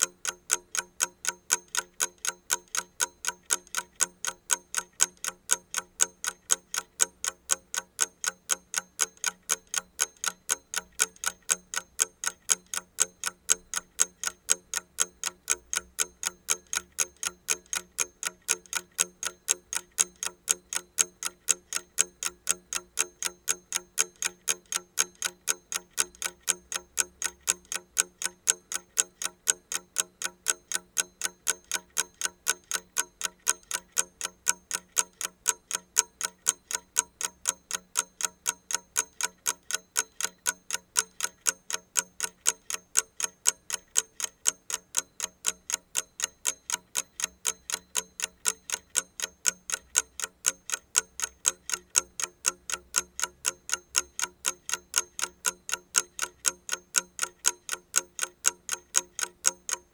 timer.mp3